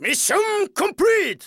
File:Falco voice sample SSBM.oga
Falco_voice_sample_SSBM.oga.mp3